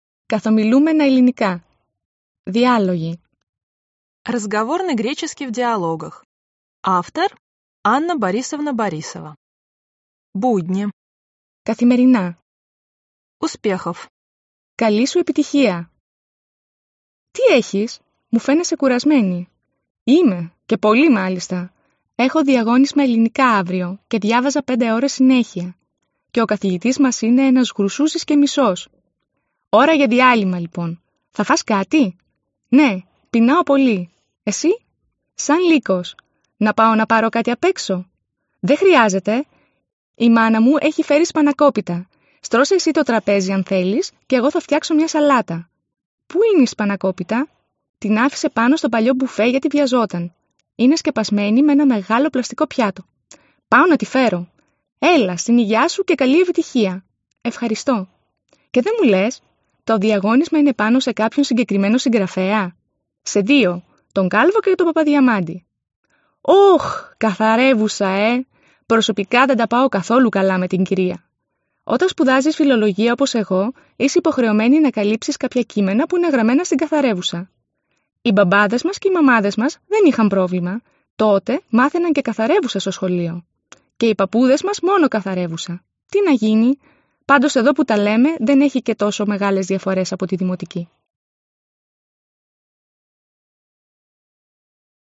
Аудиокнига Разговорный греческий в диалогах | Библиотека аудиокниг
Прослушать и бесплатно скачать фрагмент аудиокниги